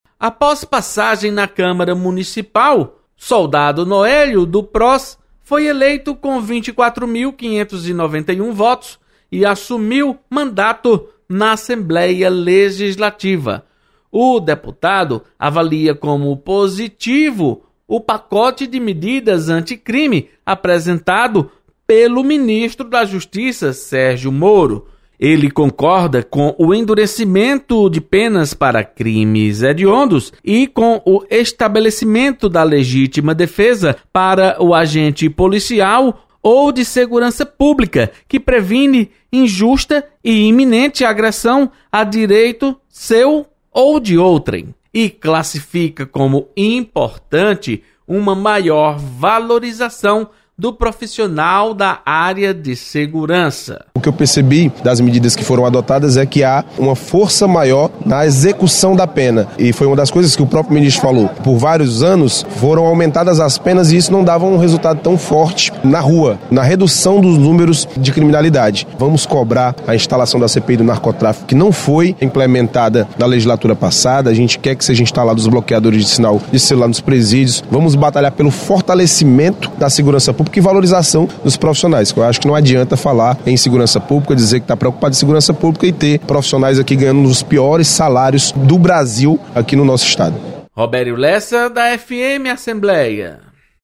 Deputado Soldado Noélio destaca compromisso com segurança pública. Repórter